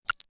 computerbeep.wav